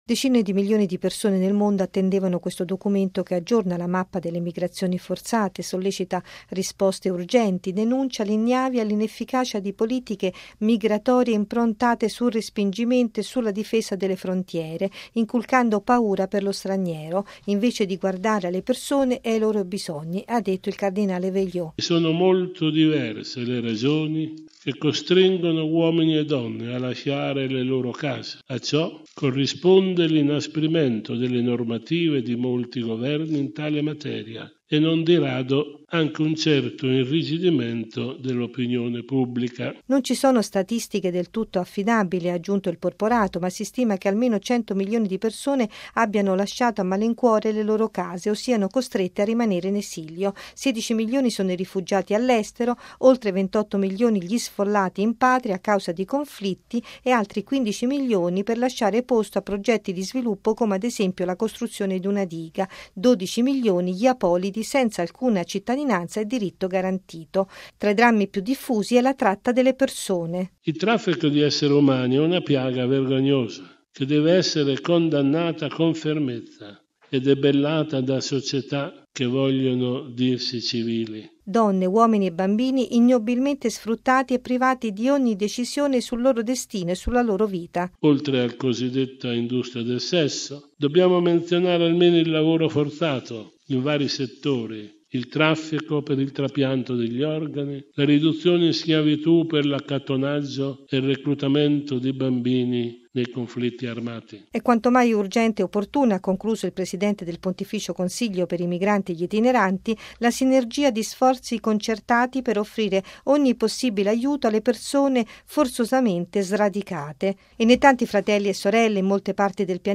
L’incontro con i giornalisti è stato presieduto dai cardinali Antonio Maria Vegliò, presidente del Pontificio Consiglio della pastorale per i migranti e gli itineranti, e Robert Sarah, presidente di Cor Unum.